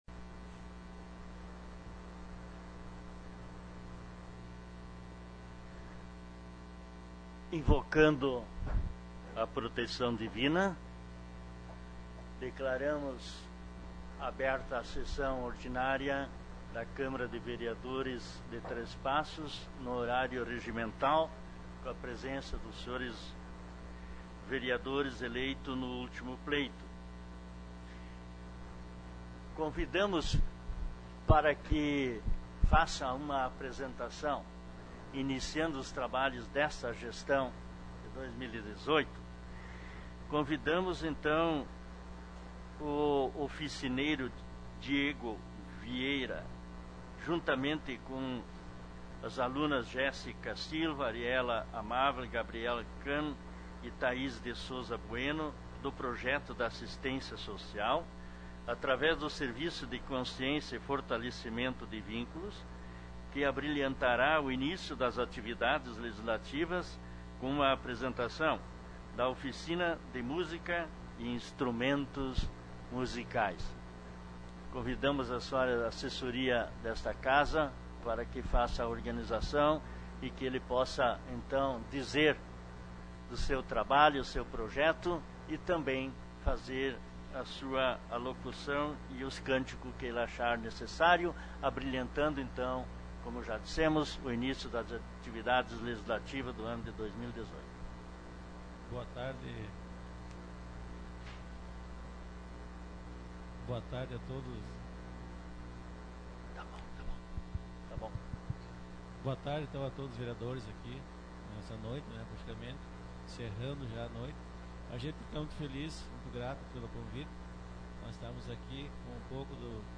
Nesta segunda-feira, 05 de fevereiro, reiniciaram as sessões ordinárias da Casa Legislativa três-passense, sob a presidência do vereador Ido Rhoden.
com a apresentação de voz e violão